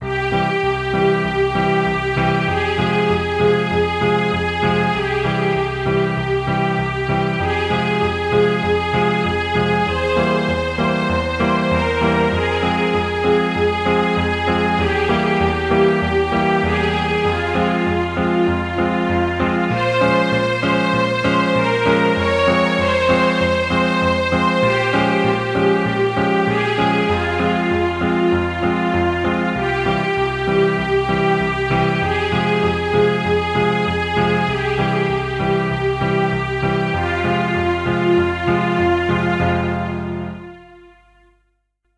Música orquestal melancólica
melancólico
orquestal